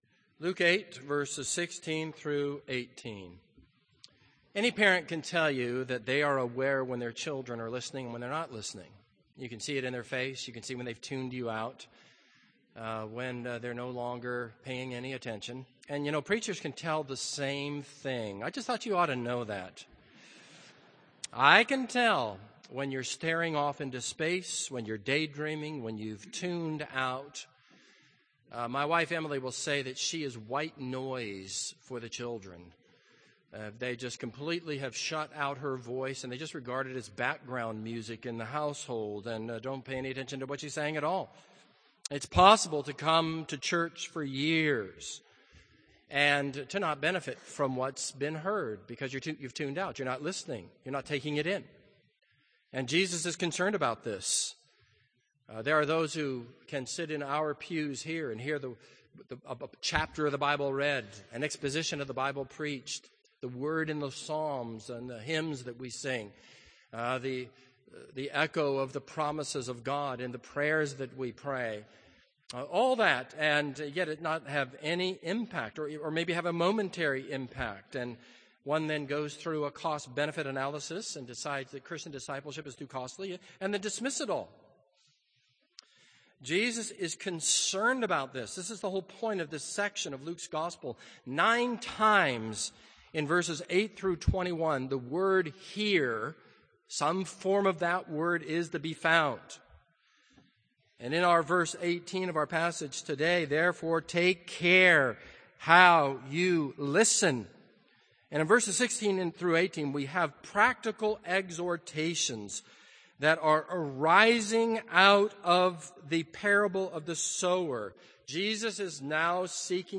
This is a sermon on Luke 8:16-18.